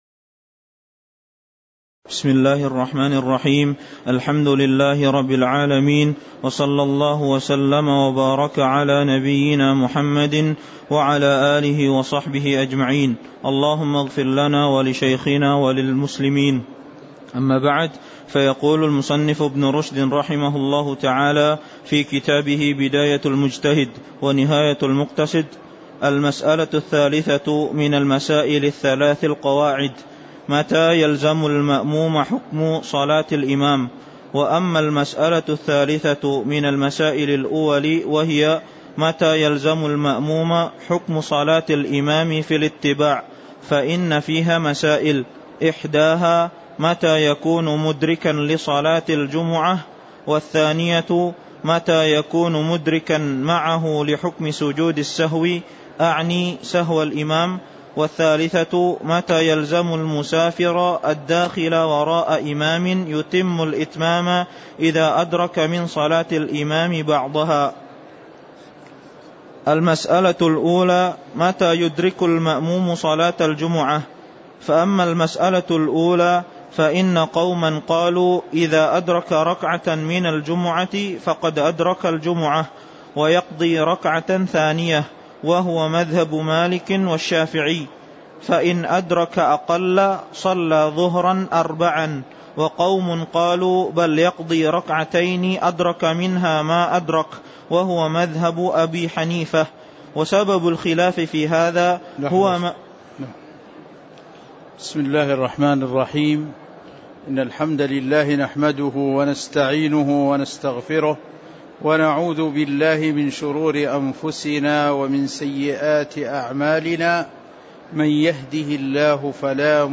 تاريخ النشر ٢٧ ربيع الأول ١٤٤٤ هـ المكان: المسجد النبوي الشيخ